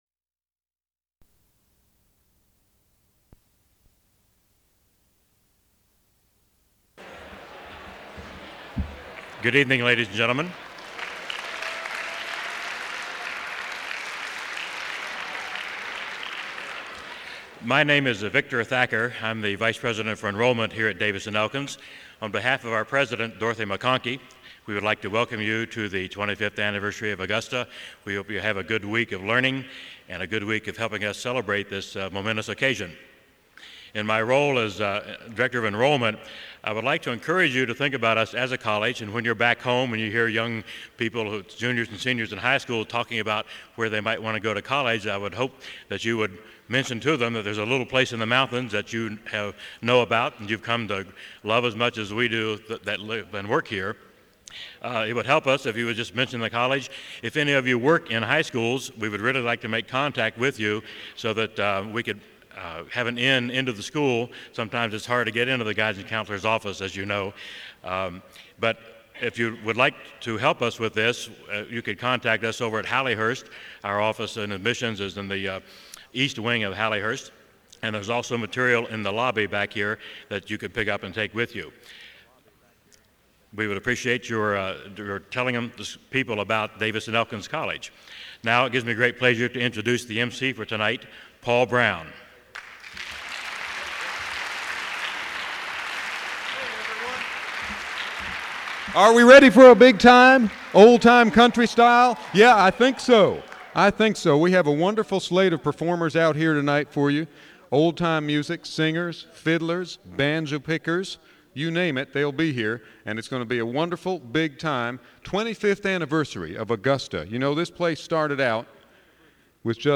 Location: Elkins, WV
The collection features recordings of Augusta concerts, cultural sessions, Master Artist visits, and more, which showcase a variety of musical and cultural traditions.